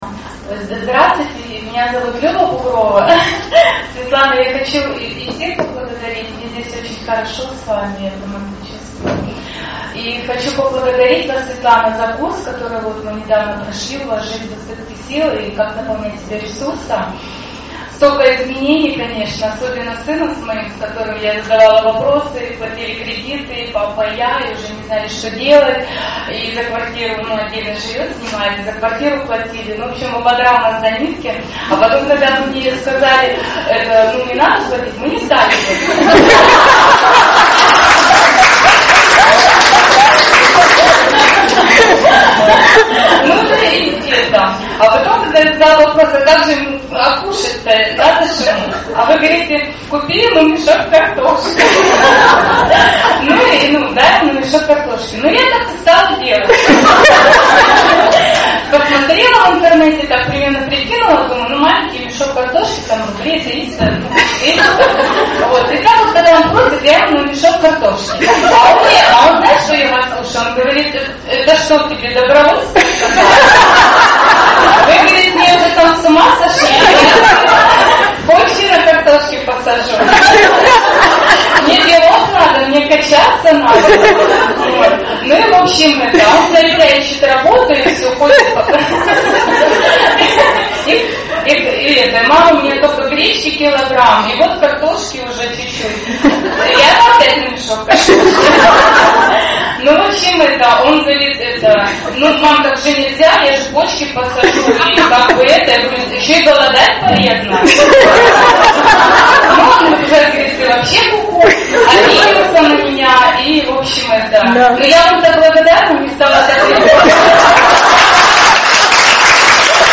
Живой рассказ о том, как мама, сохраняя в сердце любовь к взрослому сыну, перестала поддерживать его материально, тем самым сподвигнув его учиться действовать самостоятельно.
Запись встречи в Москве
Про картошку-суть понятна, но качество записи желает лучшего.